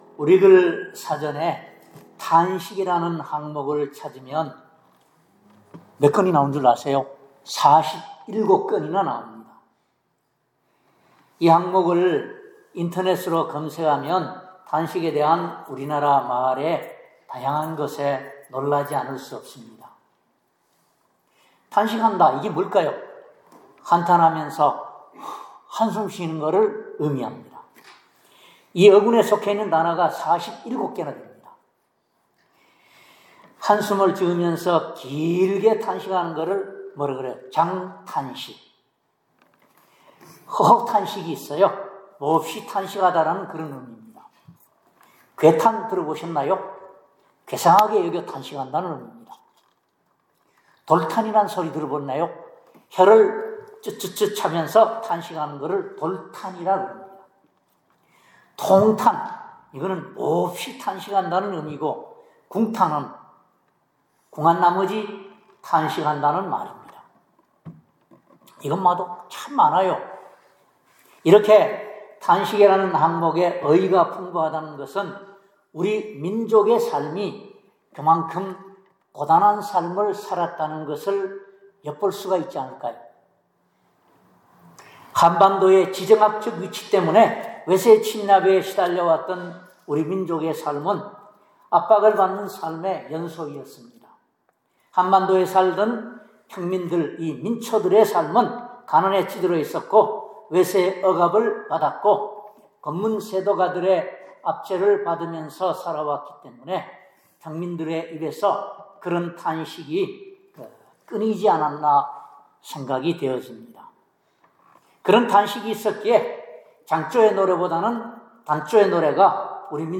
롬 8:22-27 Service Type: 주일예배 우리글 사전에 ‘탄식’이라는 항목을 찾으면 총 47건이 수록되어 있습니다.